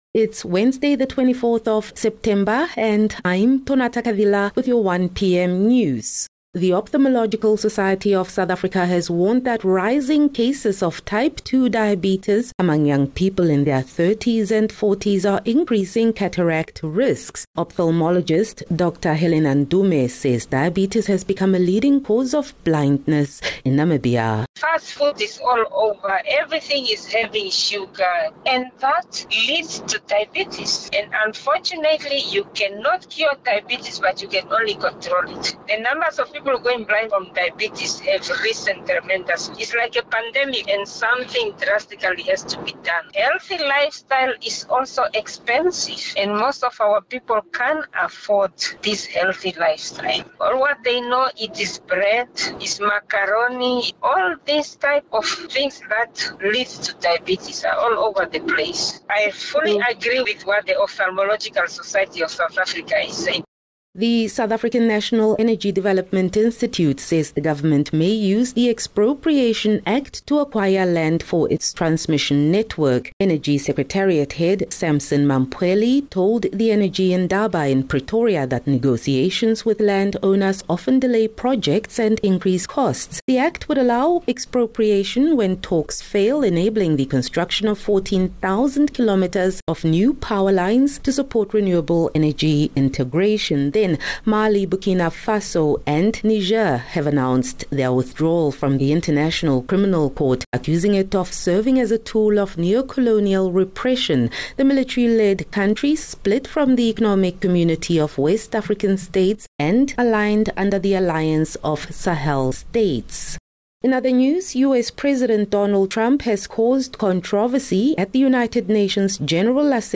24 Sep 24 September - 1 pm news